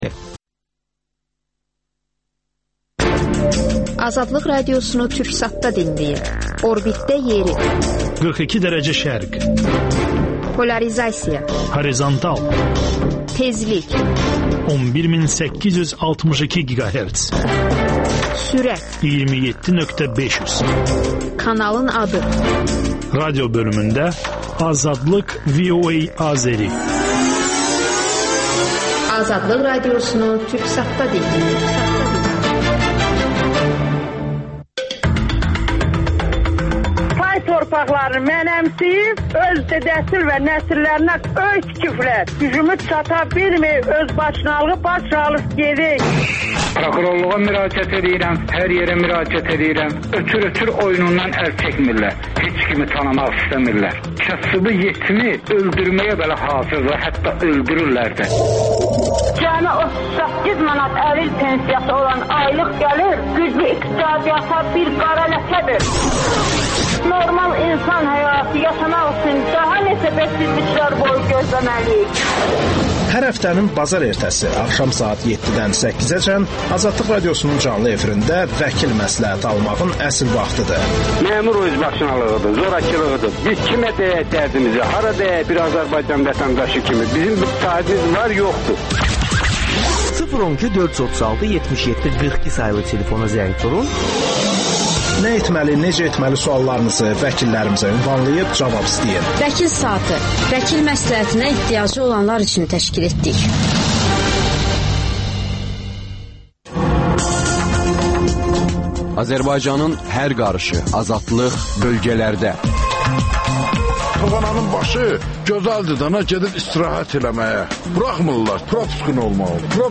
- Azərbaycan Ordusunu güclü ordu saymaq olar? AzadlıqRadiosunun müxbirləri ölkə və dünyadakı bu və başqa olaylardan canlı efirdə söz açırlar.